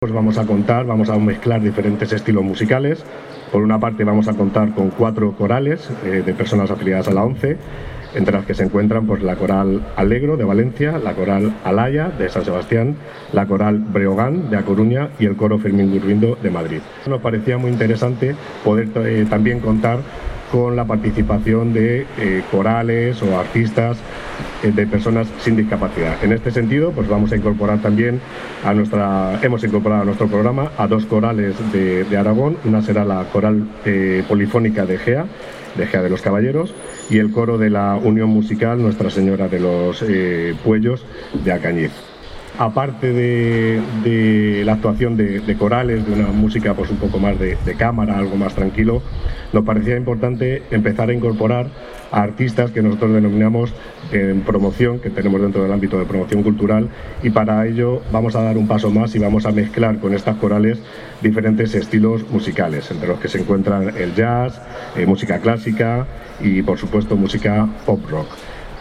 durante la presentación